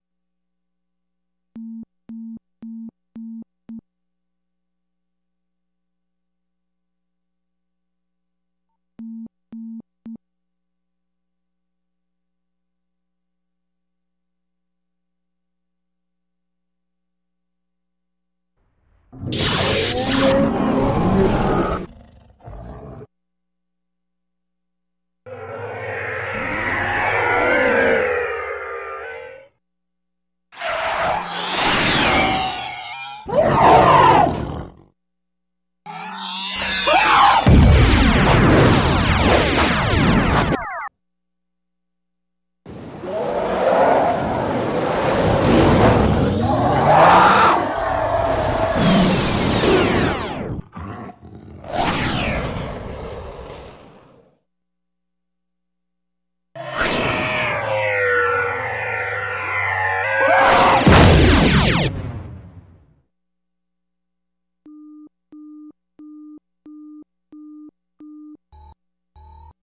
Arcade Bad Guy